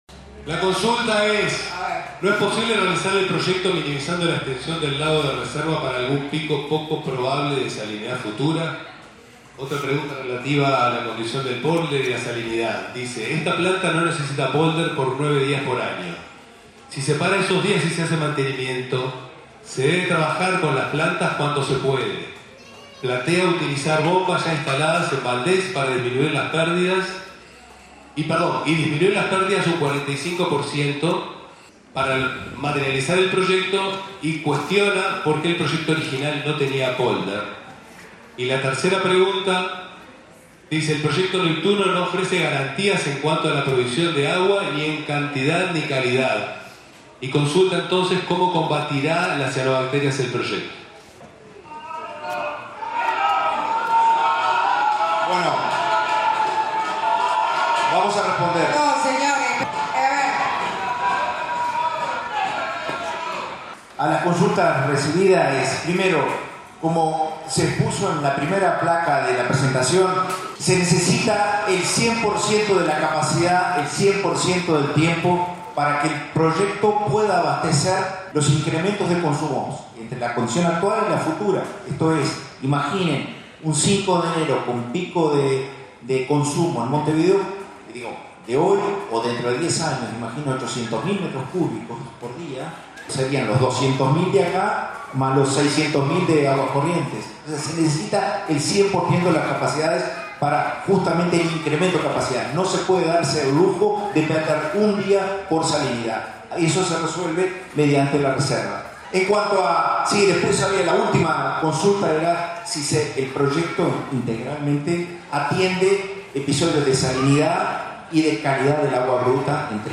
Como estaba previsto, desde las últimas horas de la tarde de ayer, se realizó, convocada por el Ministerio de Ambiente,  en el Club San Rafael, de Rafael Perazza,  la «Audiencia Pública» del «Proyecto Arazatí», instancia en la que participaron las máximas autoridades del Ministerio de Ambiente y OSE.